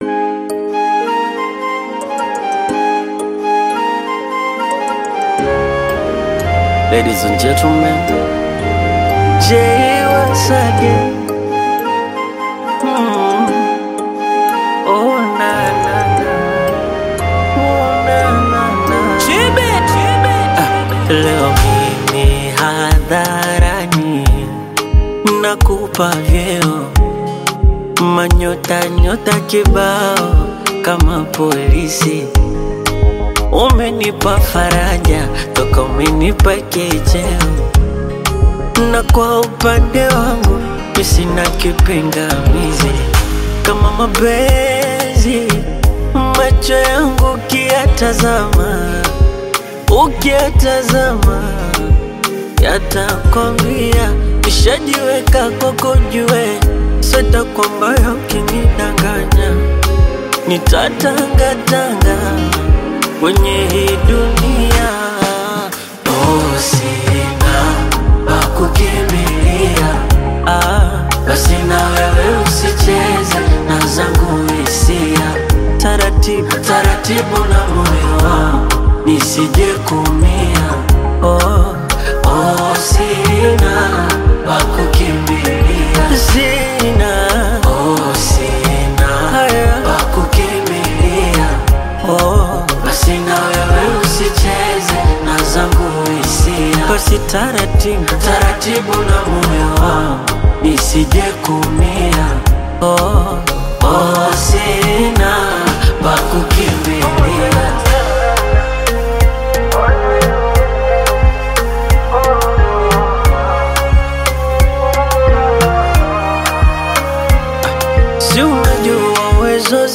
Genre: Bongo Flava